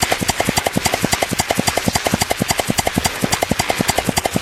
wheel_suction_cup_01.ogg